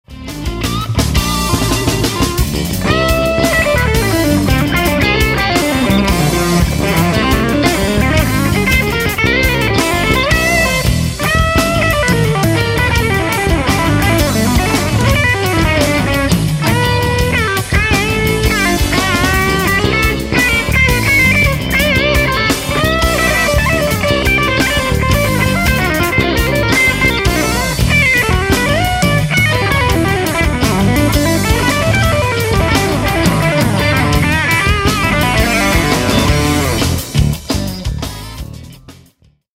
Amp is the Glaswerks SOD 50.
SM57 mic.
The HRM sounds "narrower" and more "aimed".